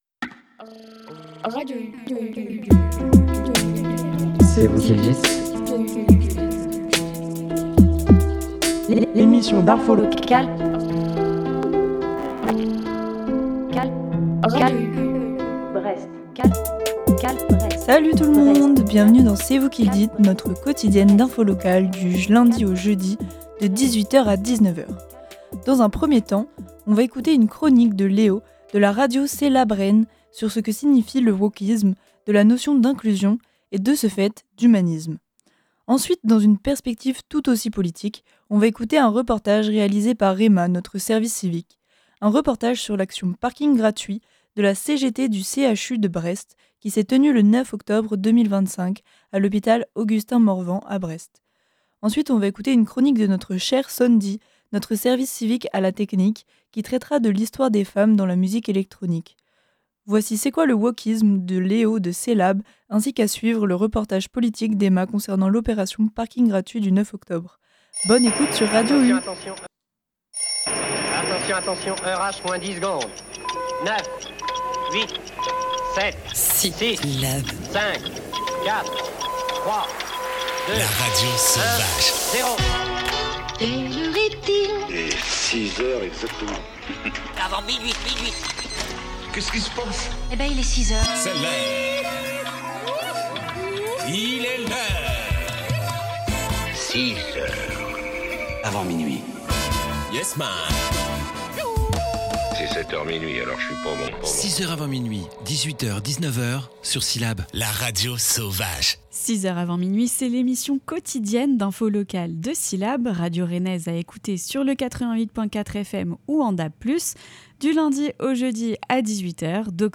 trois chroniques